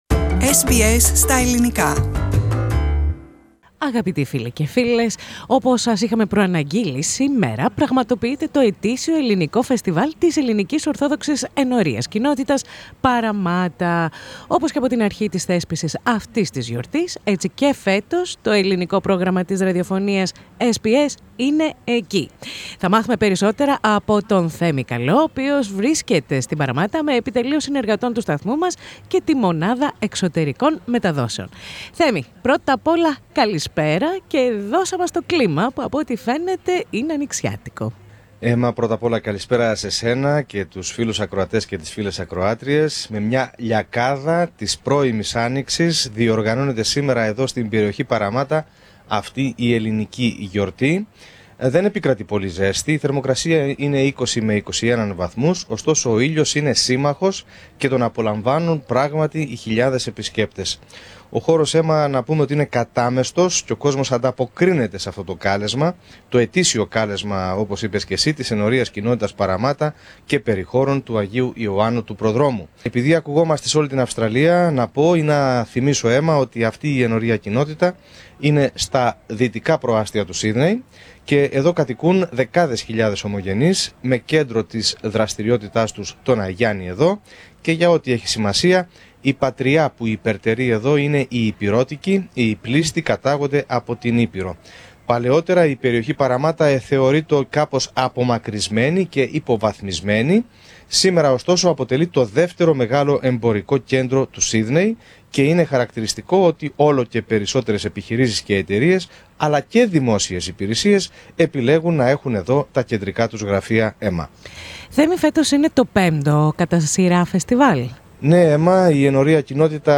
Με σύμμαχο τον καιρό ομογενείς κάθε ηλικίας και όχι μόνο είχαν την ευκαιρία να γευτούν ελληνικές λιχουδιές, αλλά και να απολαύσουν ελληνική μουσική από συγκροτήματα που βρέθηκαν στη σκηνή του Φεστιβάλ. Πατήστε Play στο Podcast που συνοδεύει την κεντρική φωτογραφία για να ακούσετε την ανταπόκριση.